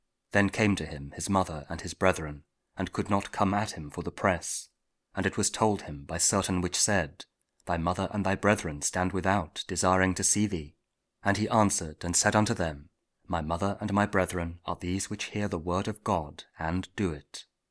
Luke 8: 19-21 – Week 25 Ordinary Time, Tuesday (King James Audio Bible KJV, Spoken Word)